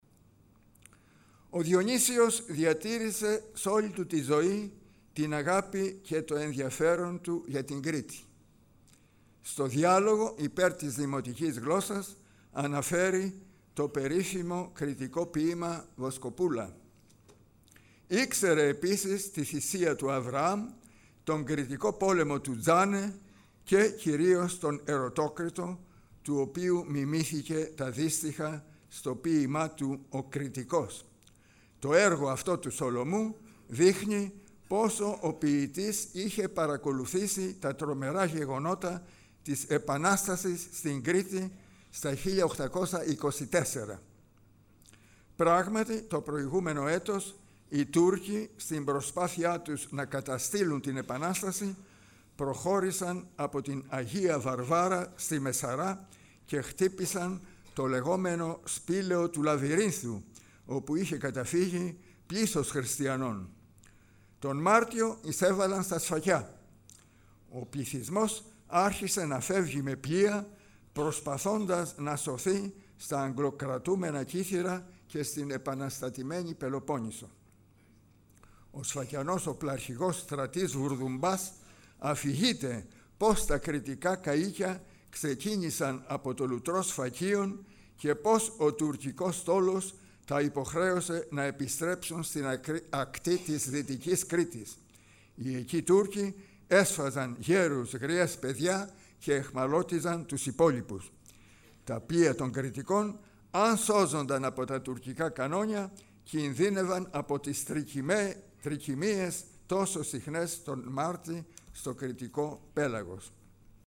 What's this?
Lecture1 ,